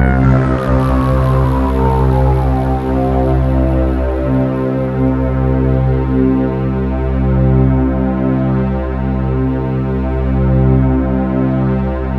Index of /90_sSampleCDs/USB Soundscan vol.13 - Ethereal Atmosphere [AKAI] 1CD/Partition C/06-POLYSYNTH